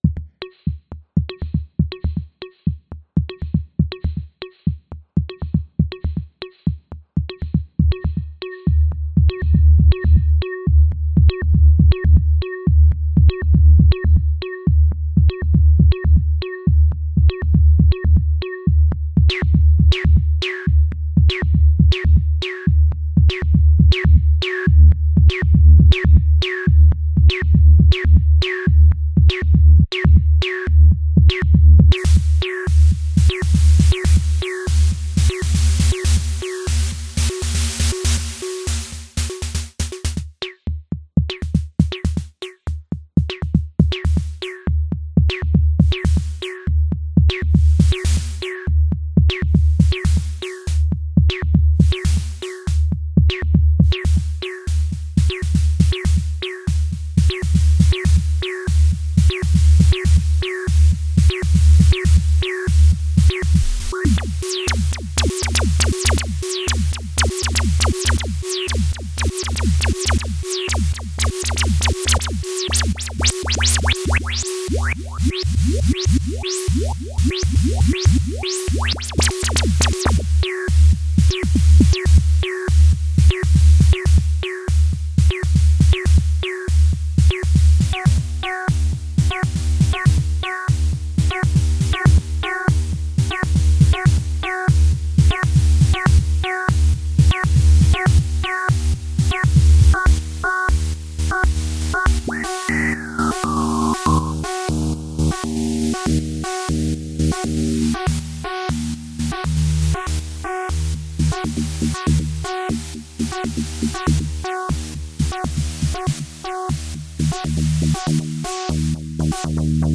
Quick percussive ish thing before work.